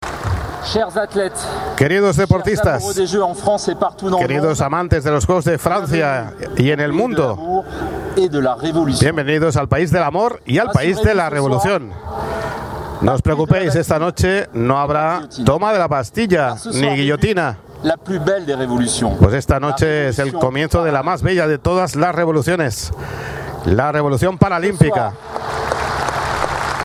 Con estas palabras Tony Estanguet formato MP3 audio(0,51 MB), presidente del Comité Organizador de los Juegos de París 2024Abre Web externa en ventana nueva, daba la bienvenida a las 169 delegaciones nacionales que competirán en los Juegos Paralímpicos hasta el próximo 8 de septiembre.
Fue la primera ceremonia inaugural de la historia de estos juegos celebrada fuera de un estadio y con un mensaje revolucionario por la inclusión social de las personas con discapacidad.